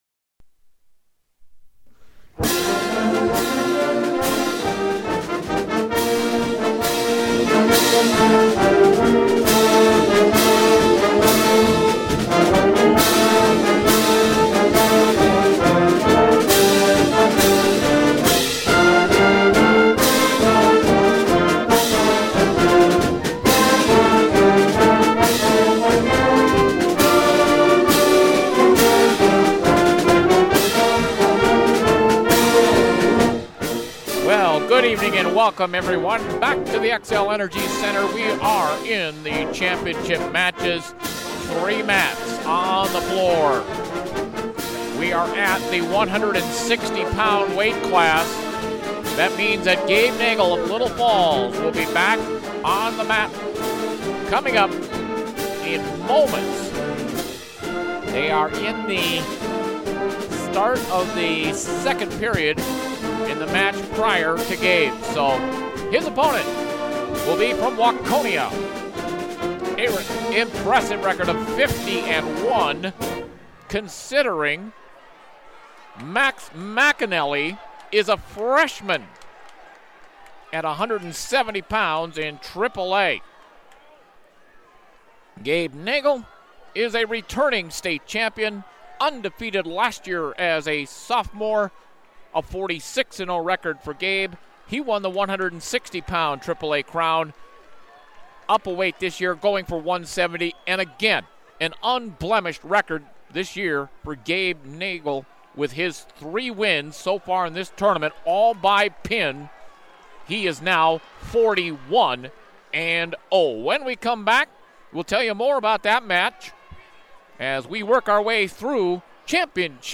at the Xcel Energy Center